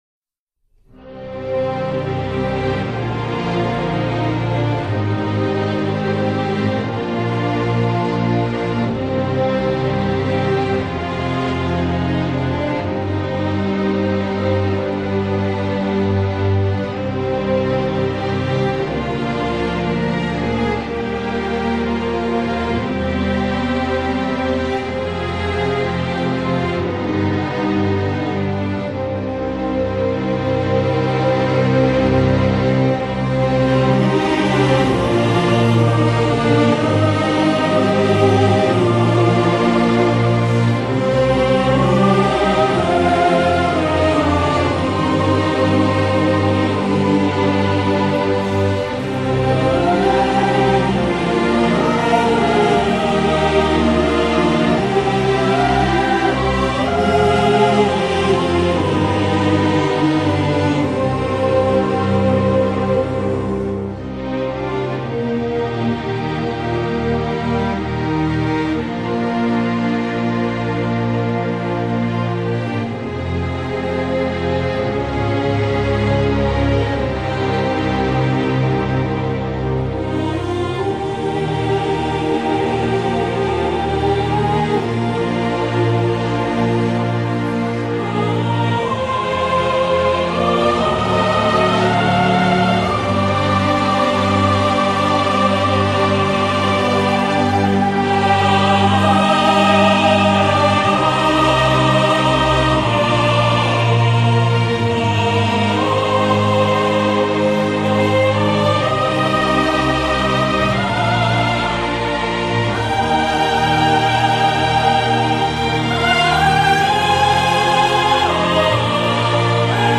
فوق العاده زیبا و احساس برانگیز